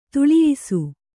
♪ tuḷiyisu